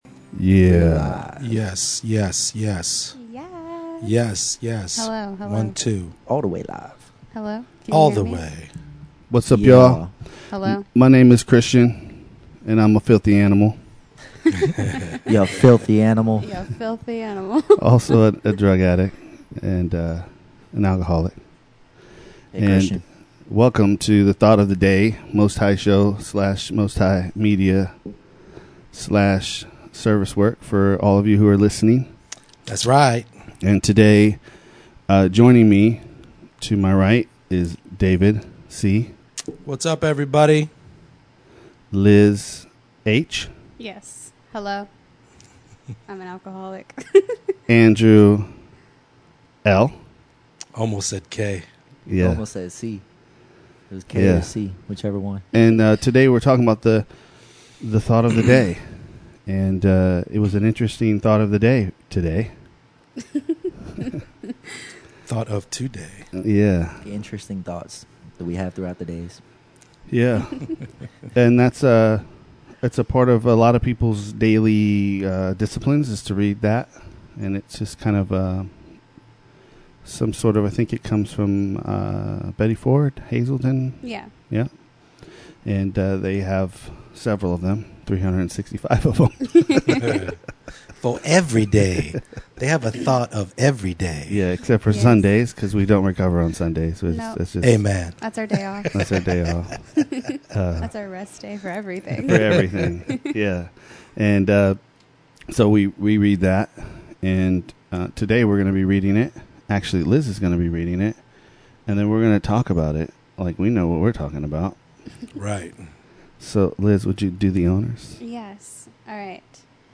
Amends, God, forgiveness and willingness are all a big part of the amends process. Listen as these four go over the amends process themselves.